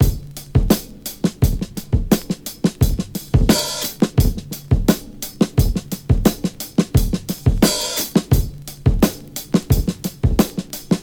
• 87 Bpm Drum Loop Sample D Key.wav
Free breakbeat sample - kick tuned to the D note. Loudest frequency: 1685Hz
87-bpm-drum-loop-sample-d-key-Sep.wav